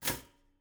sfx_交互失败.wav